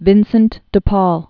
(vĭnsənt də pôl), Saint 1581-1660.